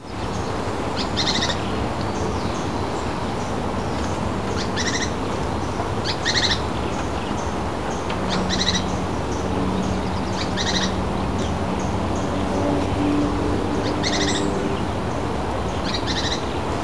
Spix´s Spinetail (Synallaxis spixi)
070919-AVE-Pijui-plomizo.mp3
Spanish Name: Pijuí Plomizo
Life Stage: Adult
Detailed location: Eco Área Avellaneda
Condition: Wild
Certainty: Photographed, Recorded vocal